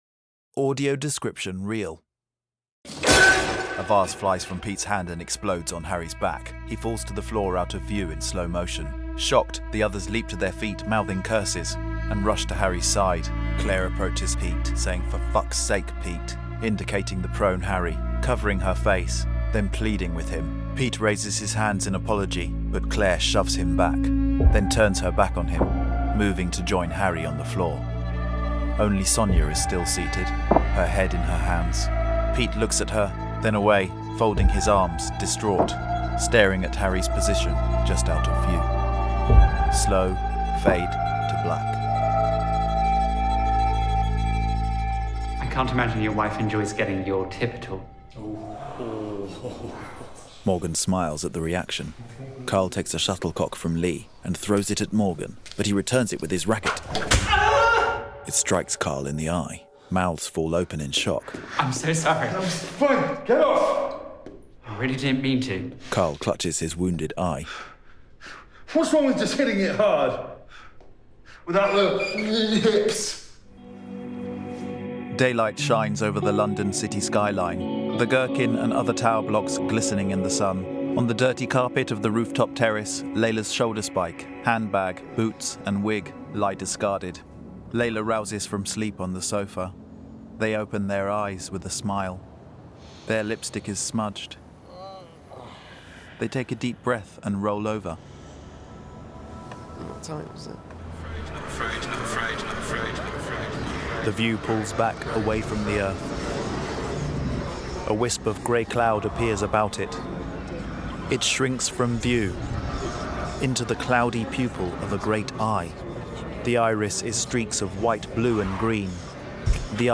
Male
English (British)
I have a versatile and expressive mid to deep British voice. I have honed my professional and sincere natural voice over many hours of audio description.
Audiobooks
Audio Description Reel
Words that describe my voice are Professional, Sincere, Expressive.